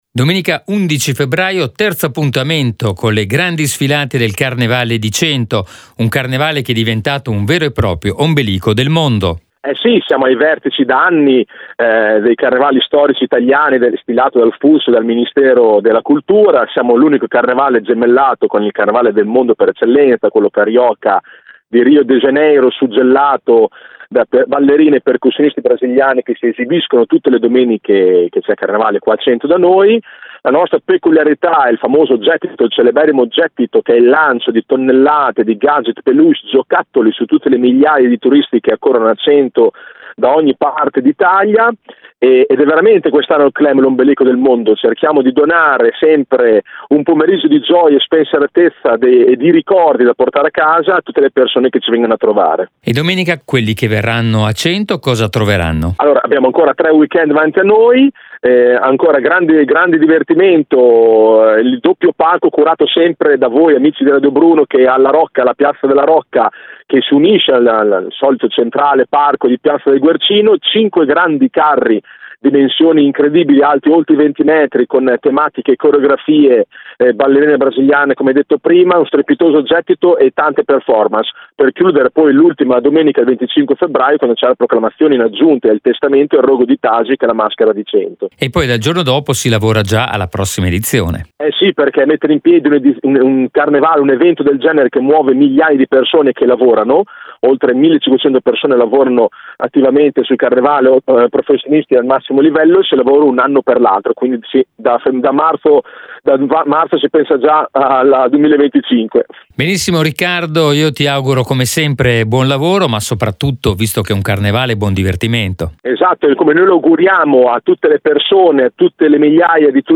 Home Magazine Interviste Terzo appuntamento con Cento Carnevale d’Europa